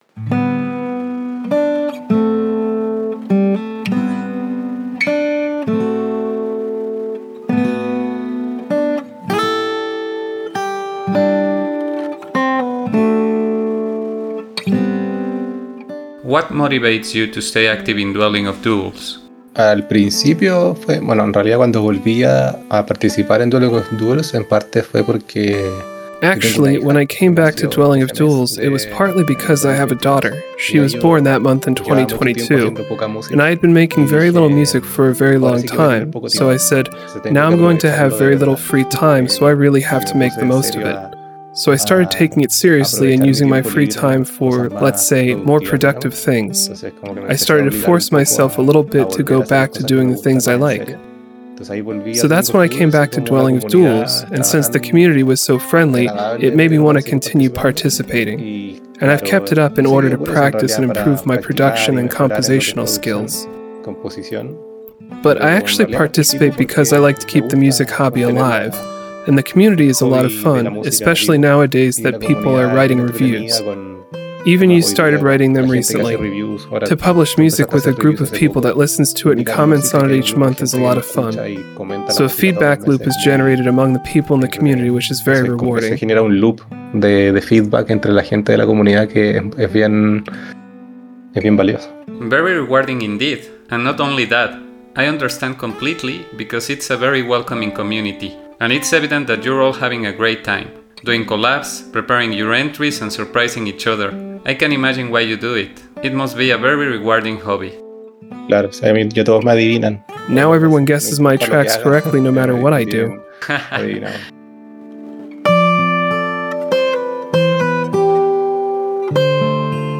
Welcome to Interview with a Dweller!
Backing Track: